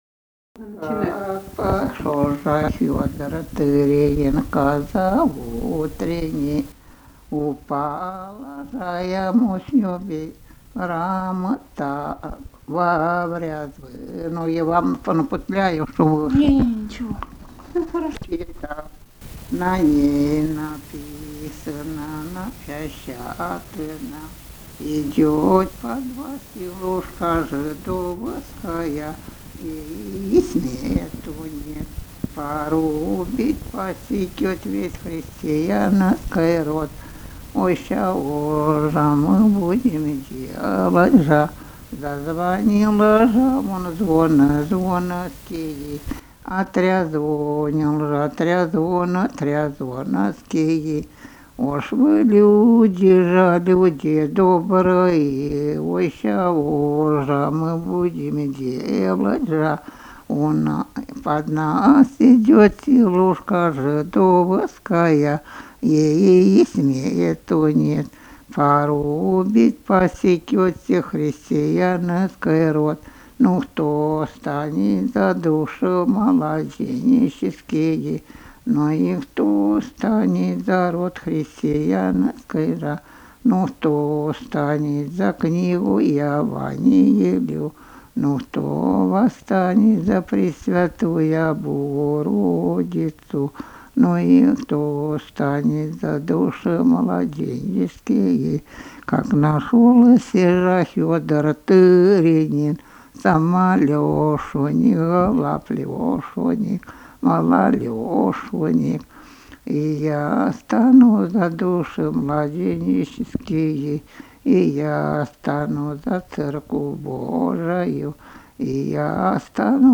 Собрание имеет звуковое приложение – диски с записью аутентичного исполнения былинных песен и духовных стихов самими носителями эпических традиций.